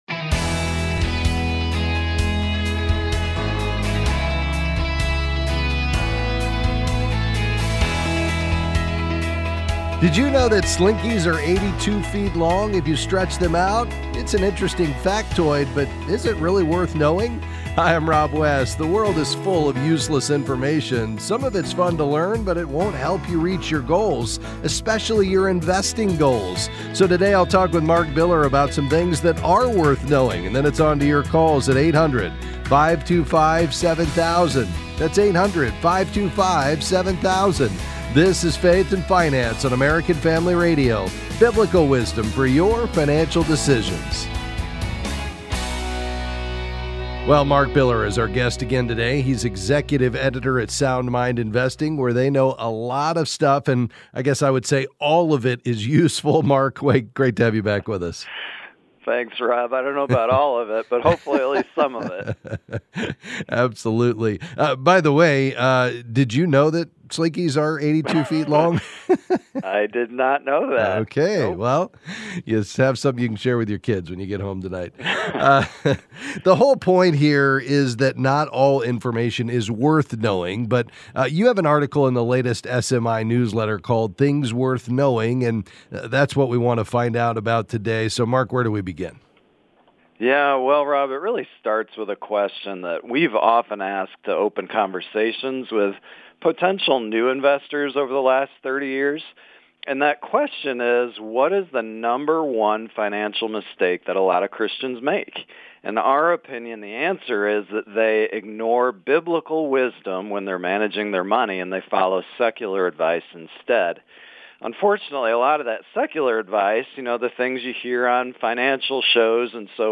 Then they will answer your calls and questions about investing and finances.